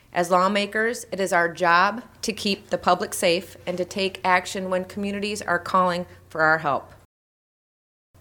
State Representative Tony McCombie says this would allow pharmacists and retail stores to sell fentanyl test strips over the counter.